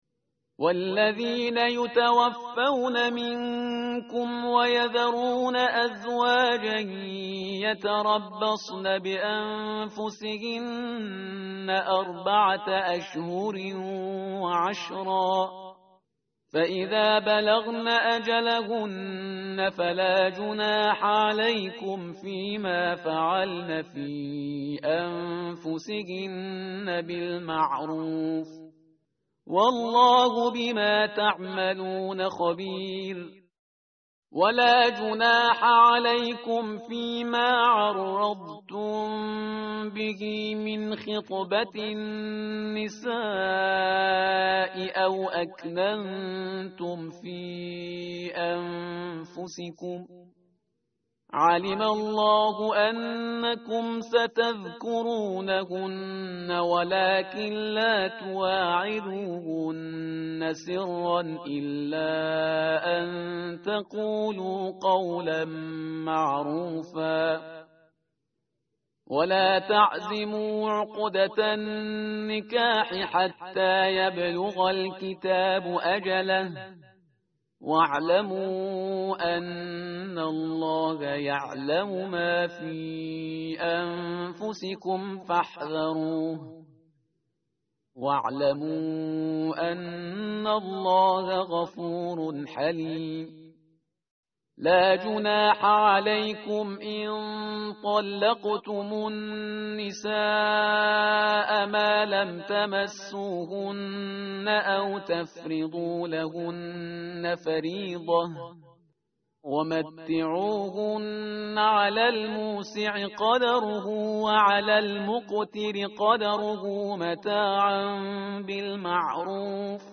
tartil_parhizgar_page_038.mp3